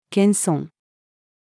謙遜-female.mp3